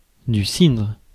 Ääntäminen
Ääntäminen France: IPA: [sidʁ] Haettu sana löytyi näillä lähdekielillä: ranska Käännös 1. ябълково вино {n} Suku: m .